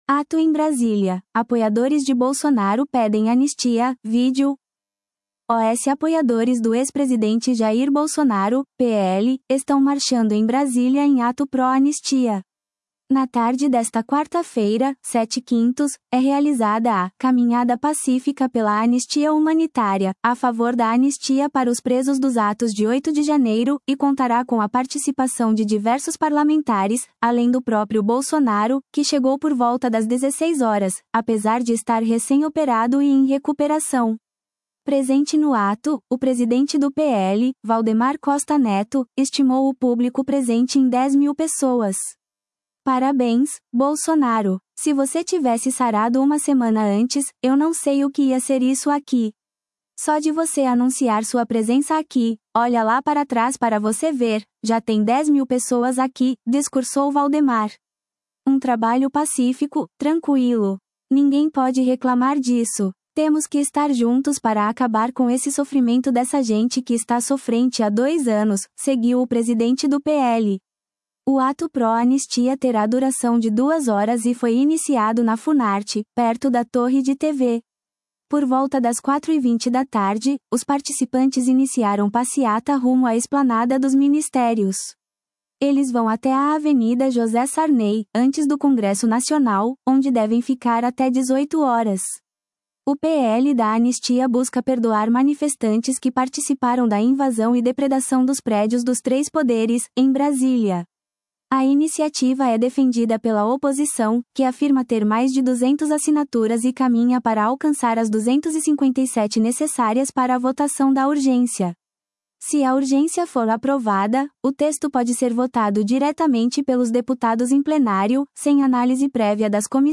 Ato em Brasília: Apoiadores de Bolsonaro pedem anistia – Vídeo
Presente no ato, o presidente do PL, Valdemar Costa Neto, estimou o público presente em 10 mil pessoas.
Por volta das 16h20, os participantes iniciaram passeata rumo à Esplanada dos Ministérios.